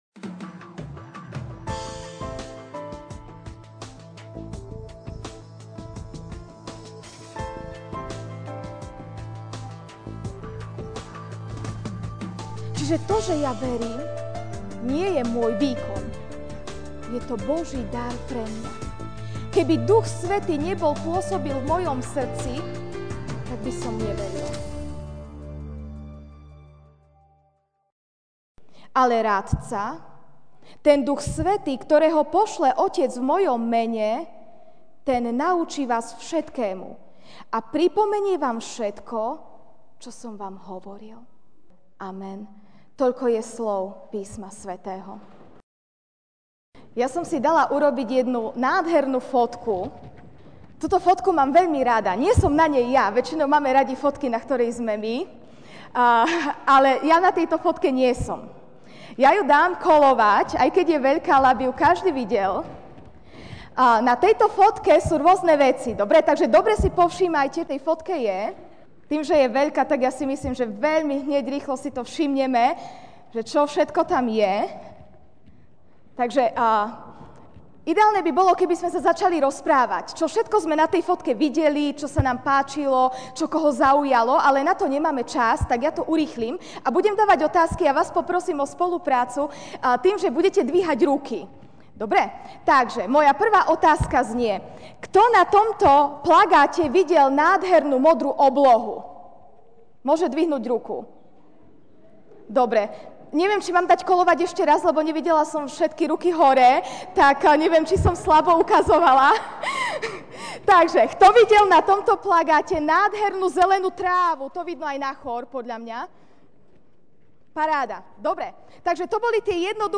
jún 04, 2017 Duch Svätý MP3 SUBSCRIBE on iTunes(Podcast) Notes Sermons in this Series Ranná kázeň: Duch Svätý (Ján 14, 26) Ale Radca, ten Duch Svätý, ktorého pošle Otec v mojom mene, Ten vás naučí všetkému a pripomenie vám všetko, čo som vám hovoril.